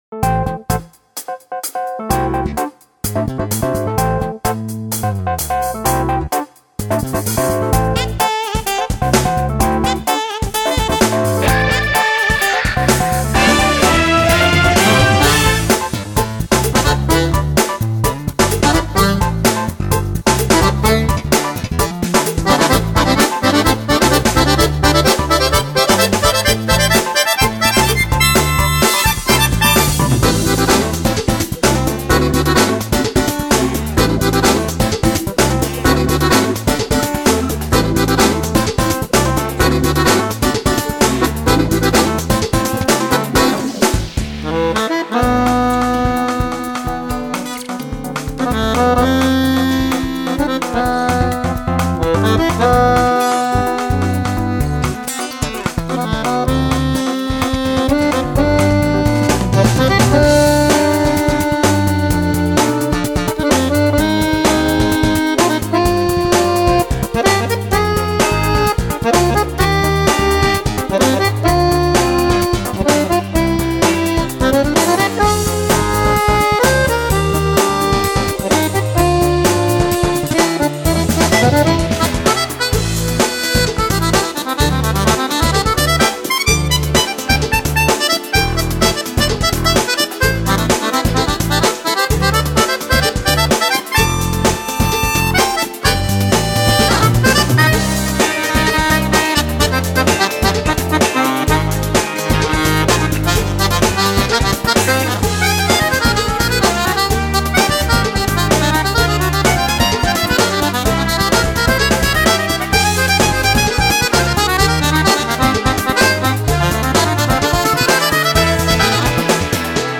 свой неповторимый стиль виртуозной игры на двух баянах